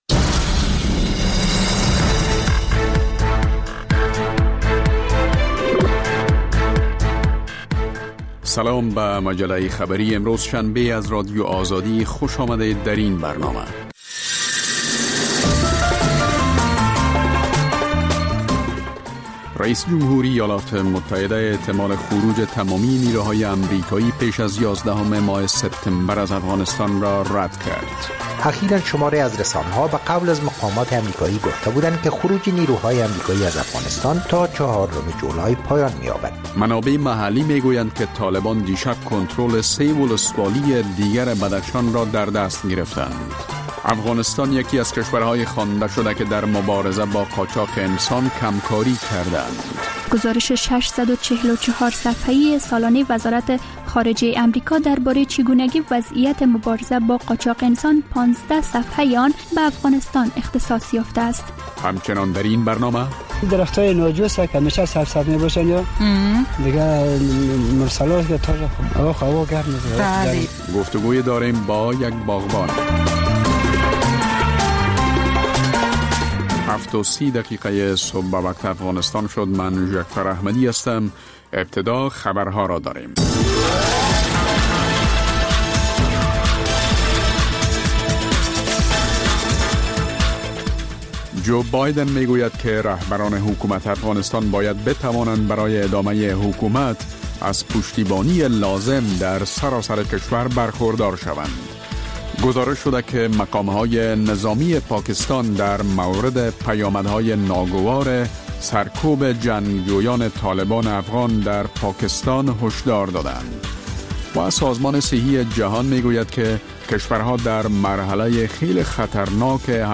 مجله خبری صبح‌گاهی